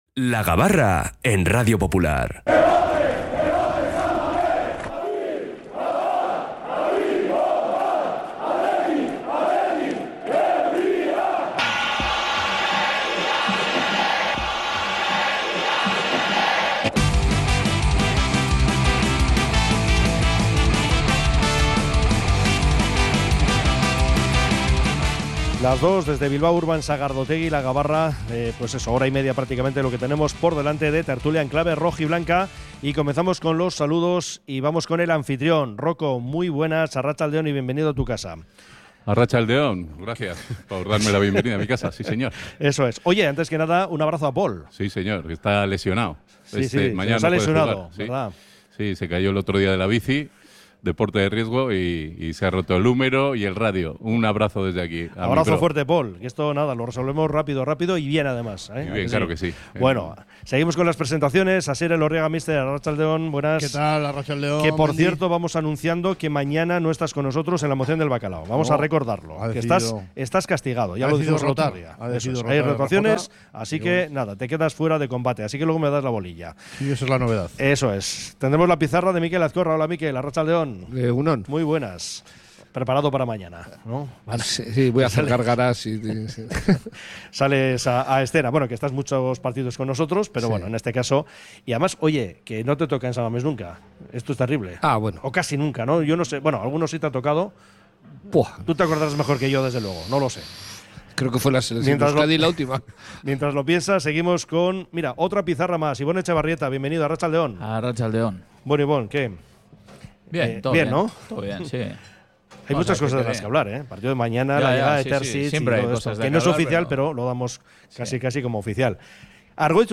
Como cada viernes desde el Bilbao Urban Sagardotegia